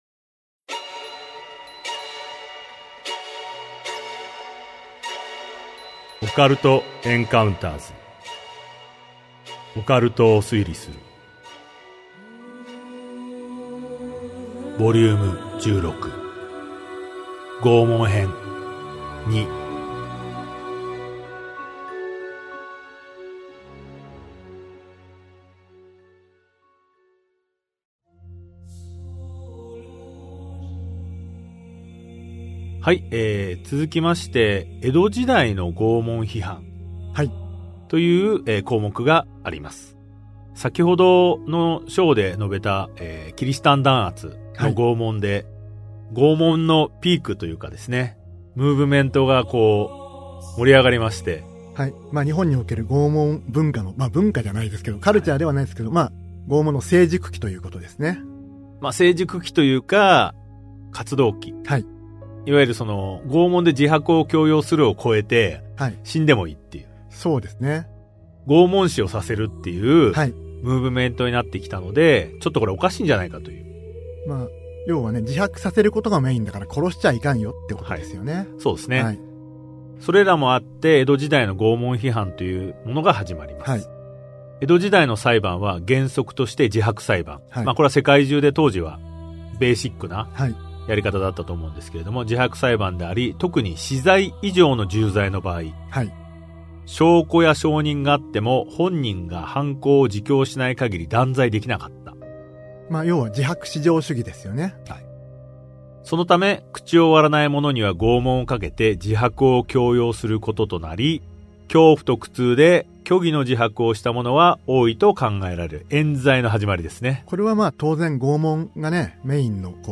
[オーディオブック] オカルト・エンカウンターズ オカルトを推理する Vol.16 拷問編2